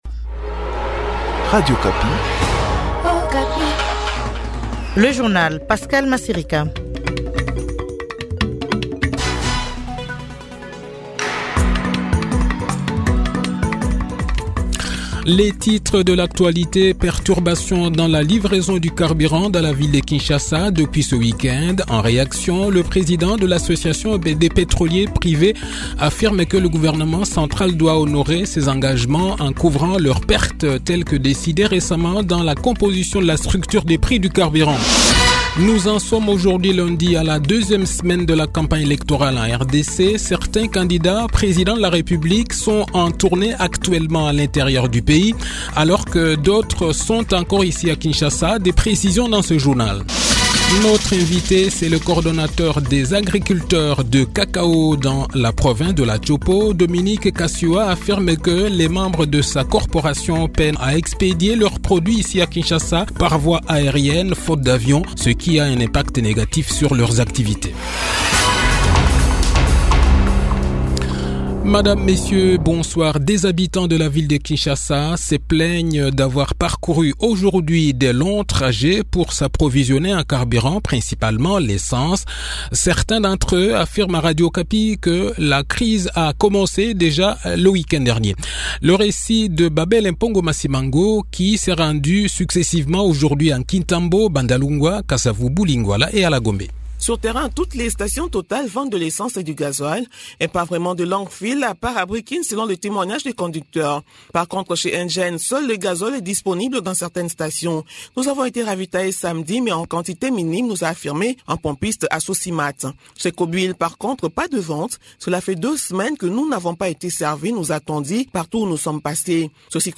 Le journal de 18 h, 27 novembre 2023